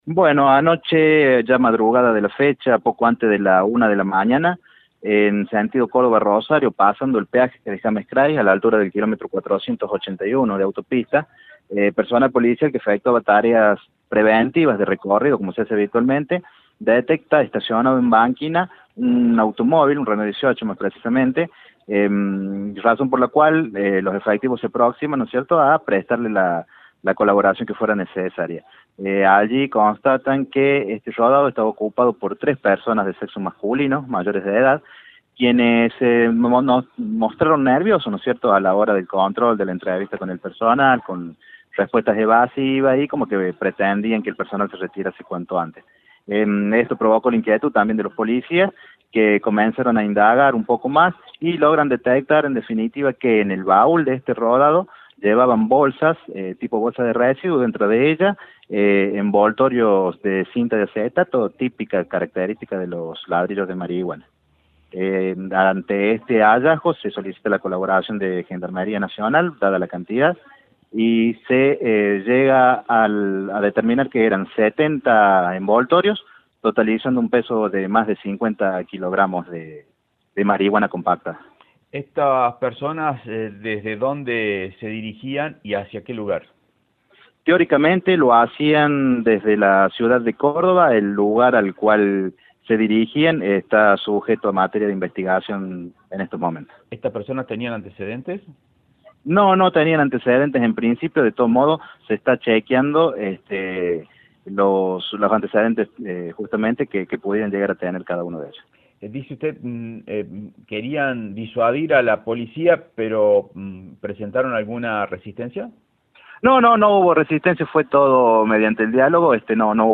Audio: Comisario Mayor Carlos Barrionuevo (Jefe Policía Caminera).